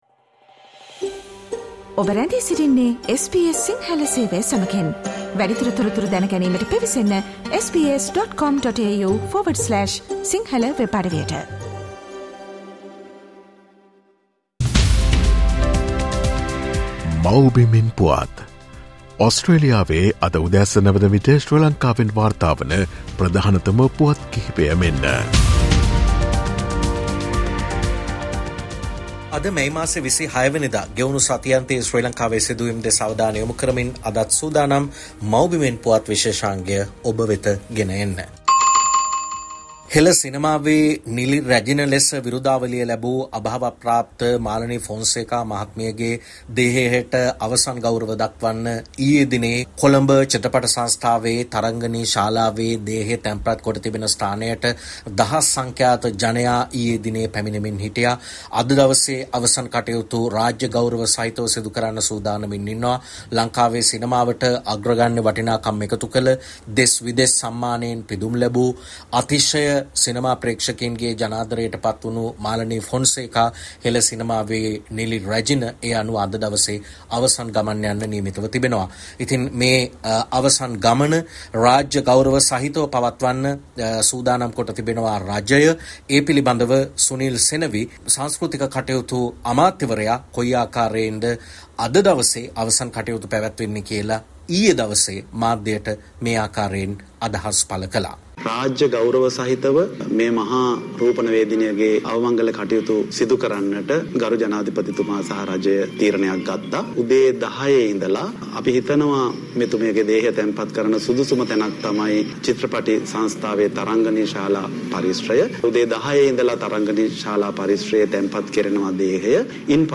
ශ්‍රී ලංකාවේ සිට වාර්තා කරයි